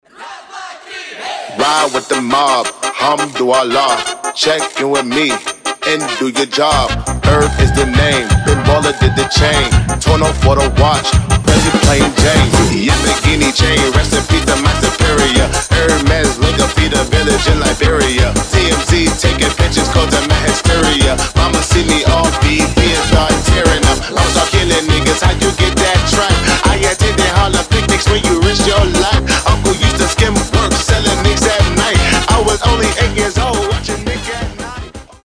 • Качество: 320, Stereo
веселые
быстрые
труба
смешные
Mashup
барабаны
ремиксы